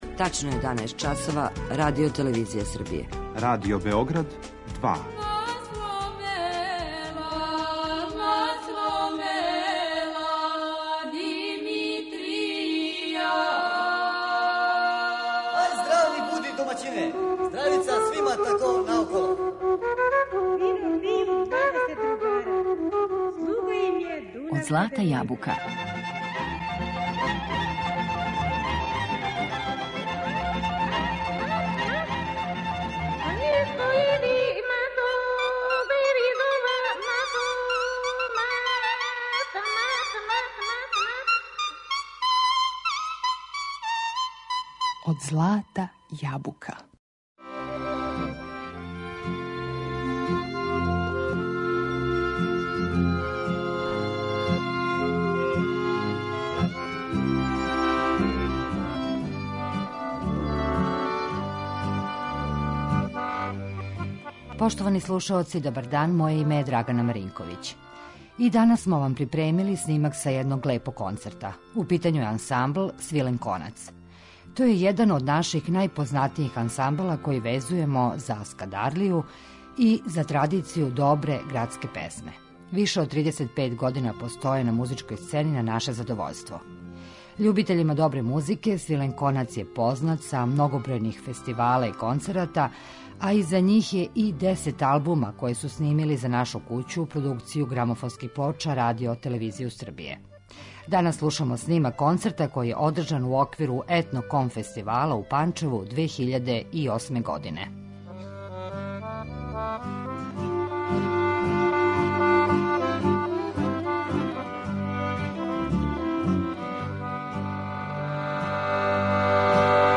И данас смо припремили снимак са једног лепог концерта, ансамбла 'Свилен конац', који везујемо за традицију добре градске песме и Скадарлију.
Слушамо снимак из Панчева, са концерта који је одржан у оквиру ETNOCOM фестивала 2008. године.